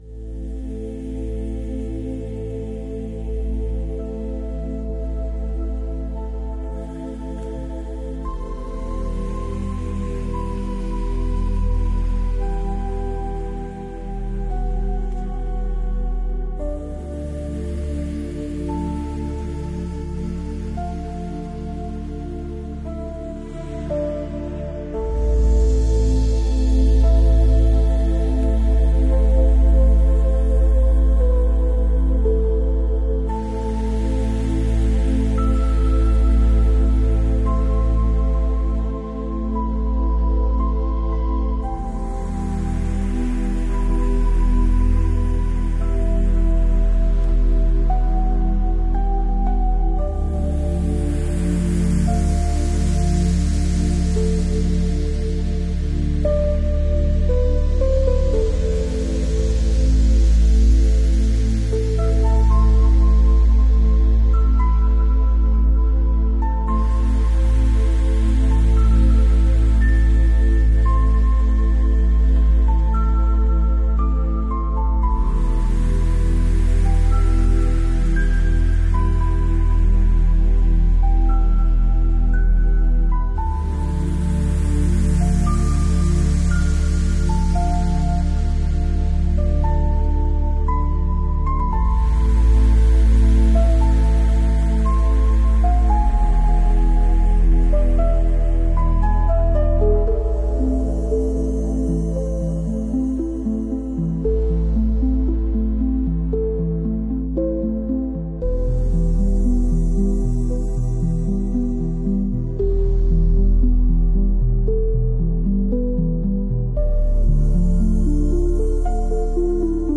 Nosey's weak spots Unboxing ASMR style! sound effects free download